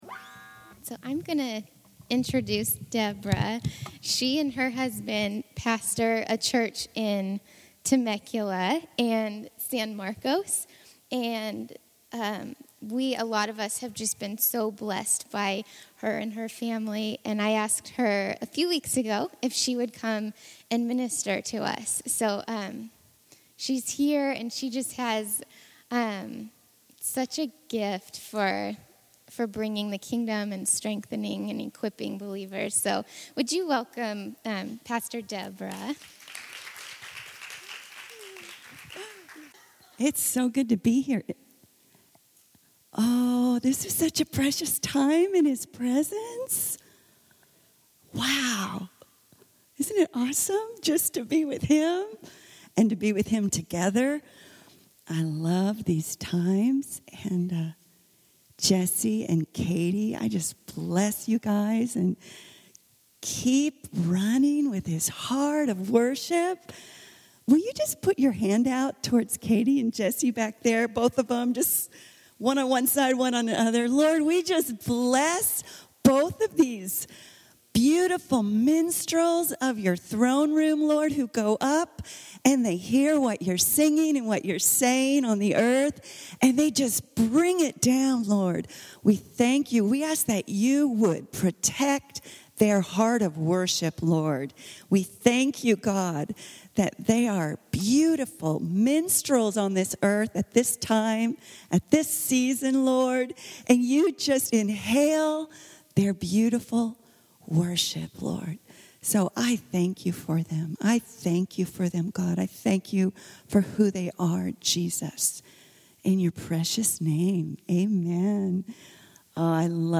Illume – Guest Teaching